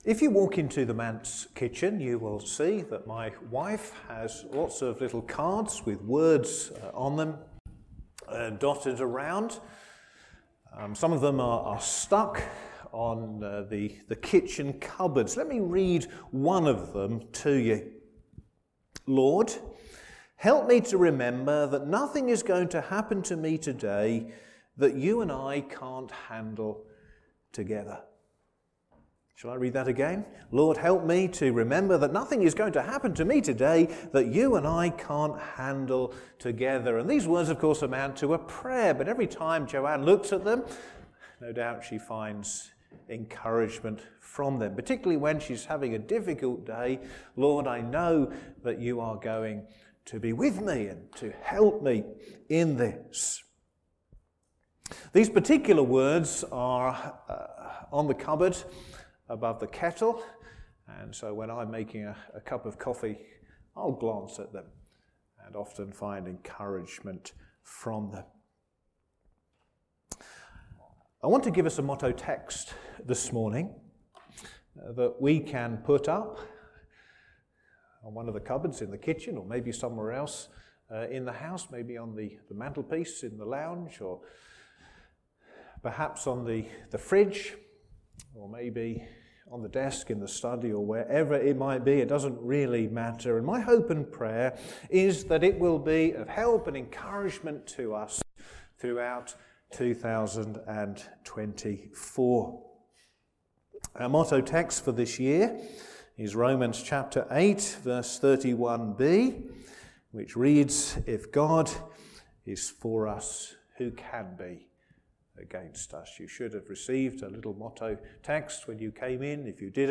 Sermon
Service Morning